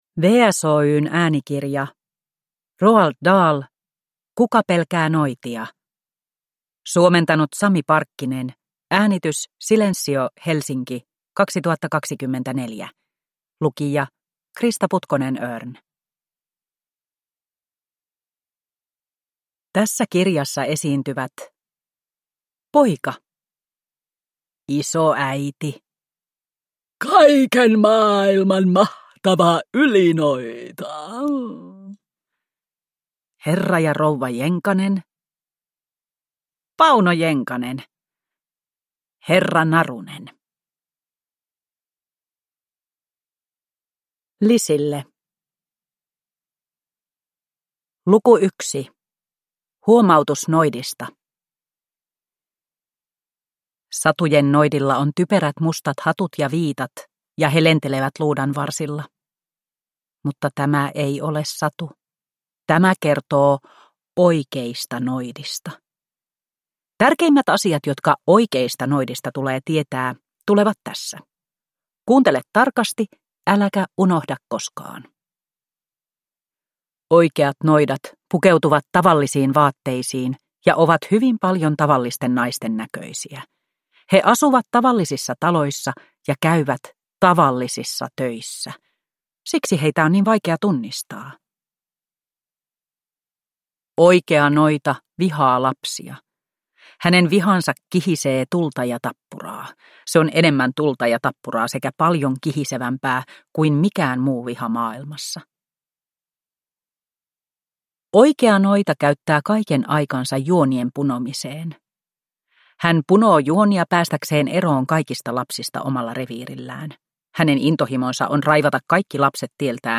Kuka pelkää noitia – Ljudbok